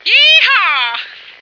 flak_m/sounds/female1/int/F1yeehaw.ogg at d2951cfe0d58603f9d9882e37cb0743b81605df2
F1yeehaw.ogg